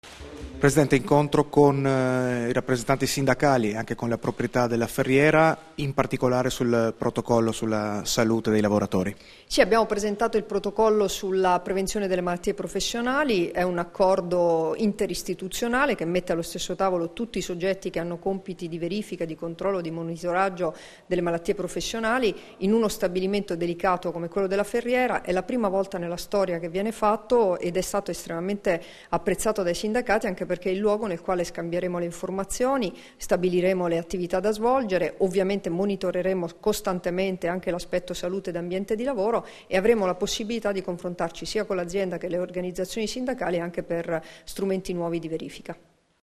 Dichiarazioni di Debora Serracchiani (Formato MP3) [740KB]
sul Protocollo per la prevenzione delle malattie professionali alla Ferriera di Servola, rilasciate a Trieste il 18 aprile 2016